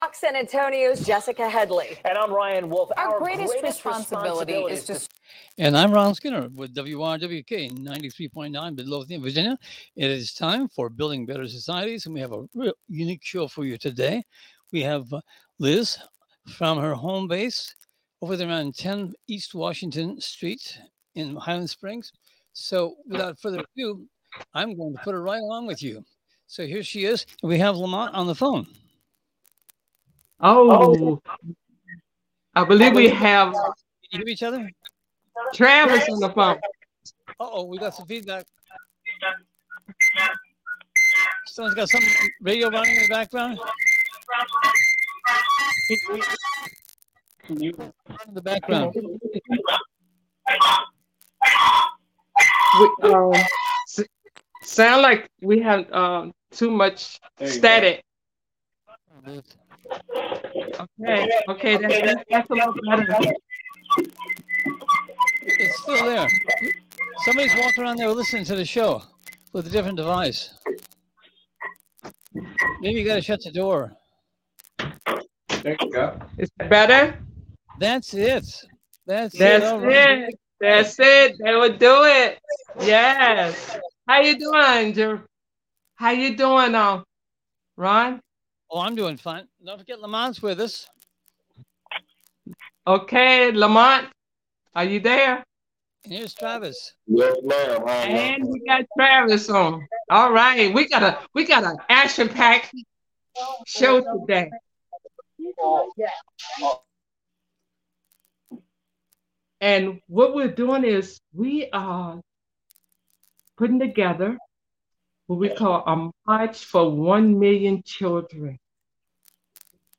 Youth Corporation, Inc celebrated the planned MILLION CHILD MARCH in the capital today by cooking out! Today’s audio is the official Building Better Societies radio show, done right at YCI’s headquarters in Highland Springs, Virginia.